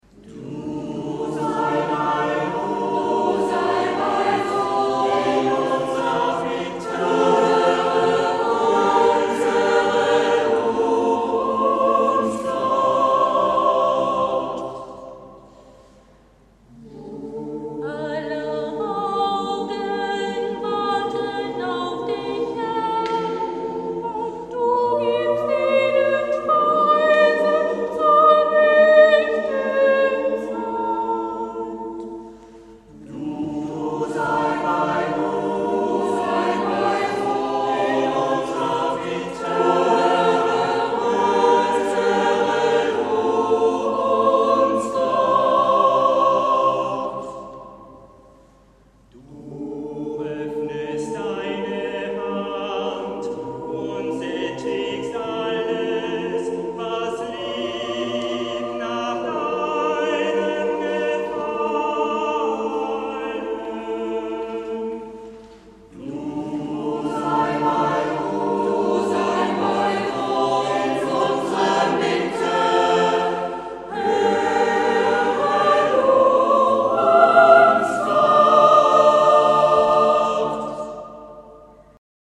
Auferstehungsmesse Ostern 2011